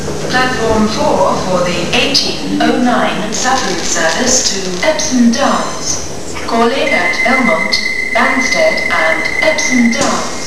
Platform announcement at Sutton for Epsom Downs service (September 2004)